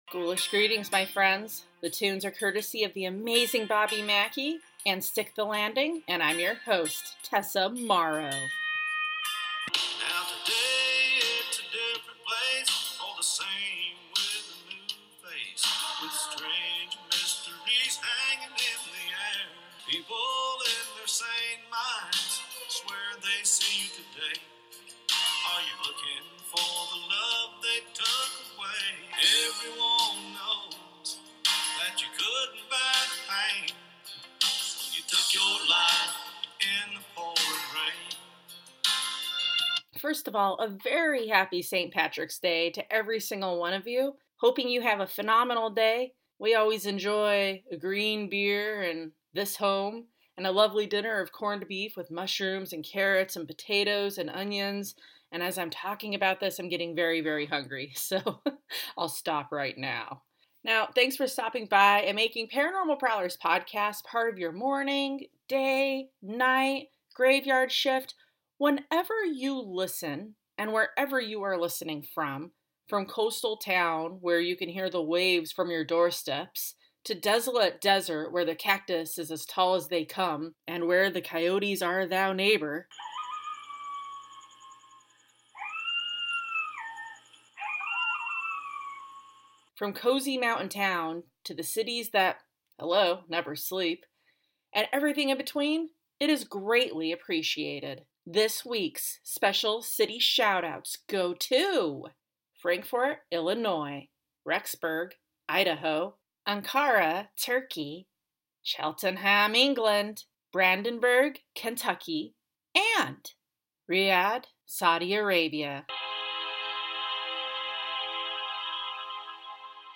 Coyote howling (Sun City West, AZ - 2022) Baby laughter (Denver, CO - 2023)